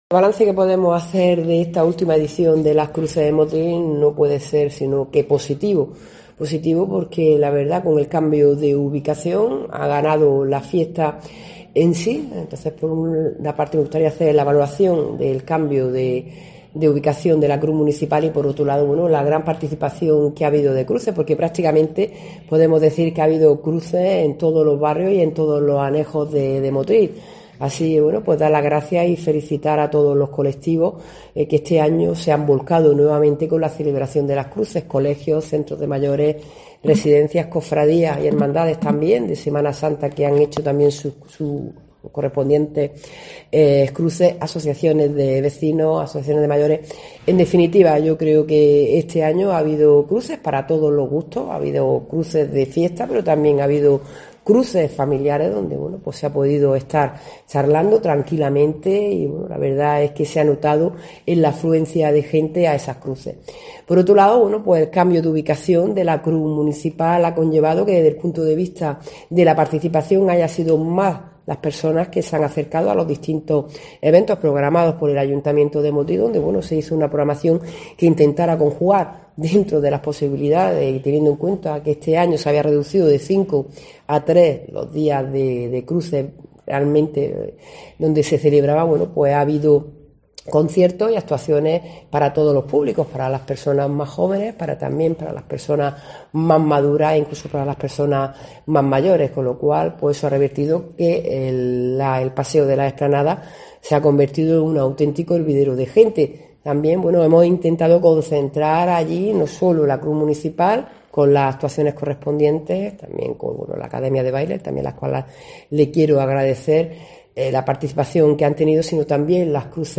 La alcaldesa comenta que el balance es muy positivo y se han disfrutado por toda la localidad